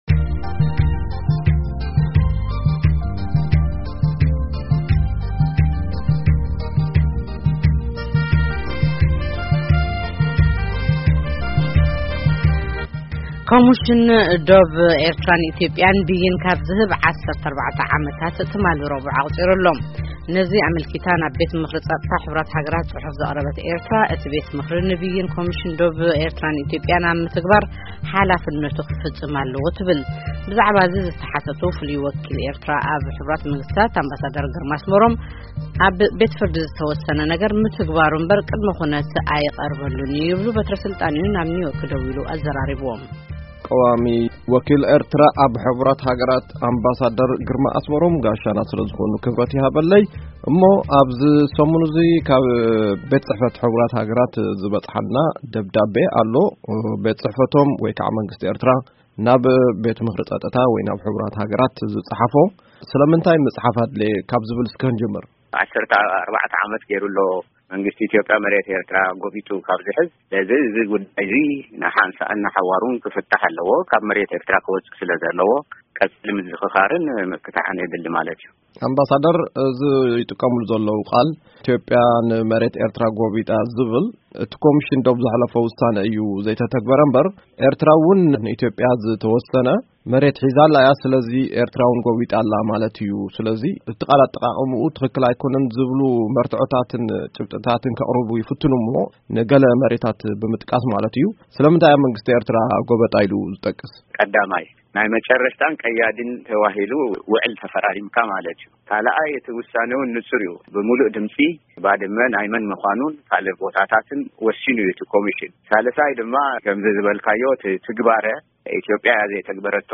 ቃለ መጠይቅ ምስ ኣምባሳደር ግርማ